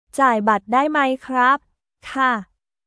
ジャーイ バット ダイ マイ クラップ/カー